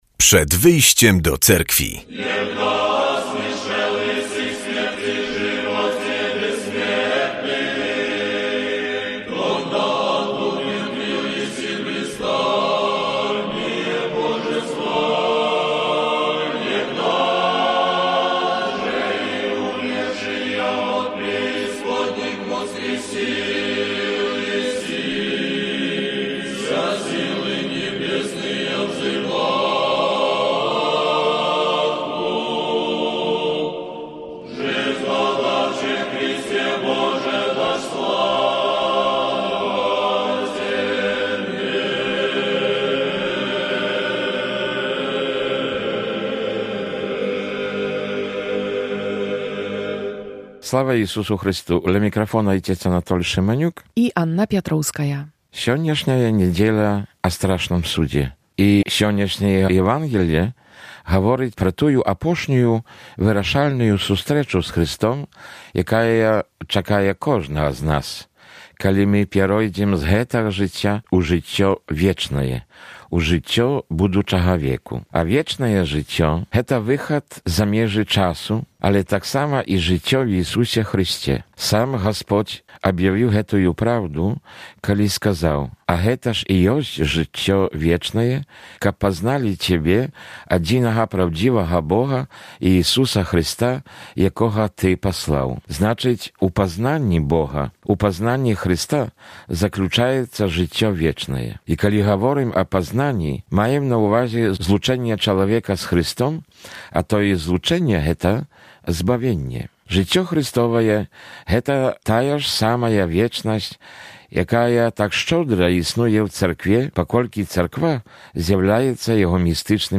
W audycji usłyszymy kazanie na temat niedzielnej Ewangelii i informacje z życia Cerkwi prawosławnej. Porozmawiamy także o znaczeniu niedziel przygotowawczych do Wielkiego Postu.